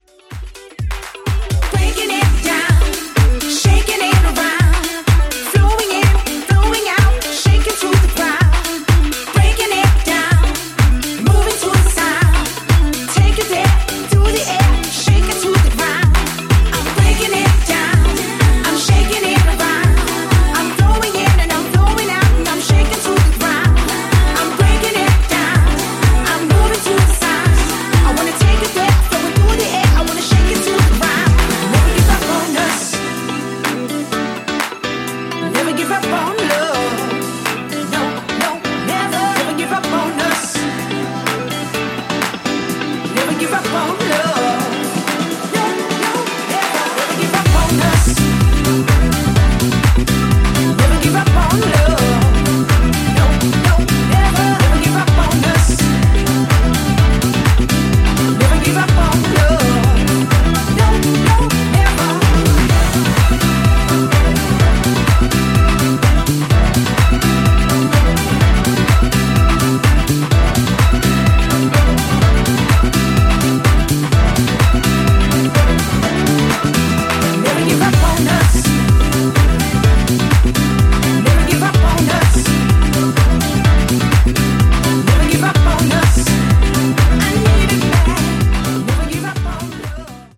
ニューディスコ、ディスコ、ファンク、といったジャンルをファンキーなソウルフルハウスで仕上げたスペシャルなトラック全6曲！
ジャンル(スタイル) NU DISCO / DISCO / HOUSE